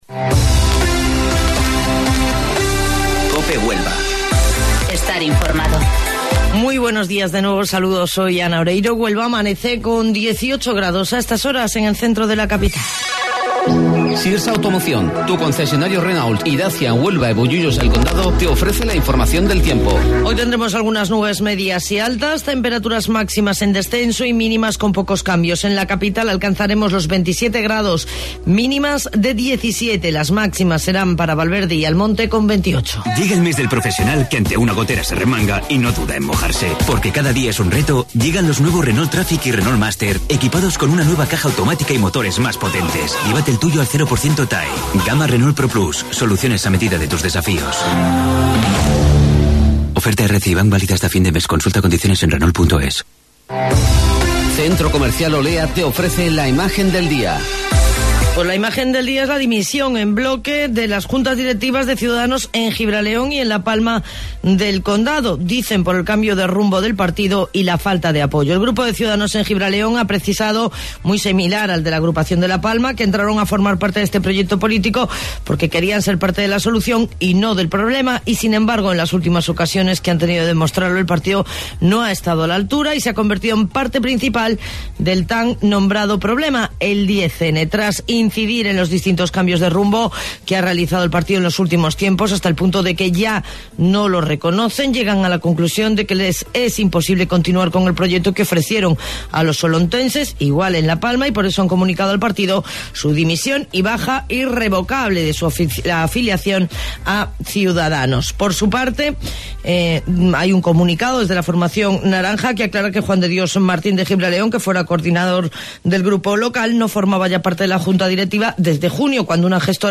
AUDIO: Informativo Local 08:25 del 11 de Octubre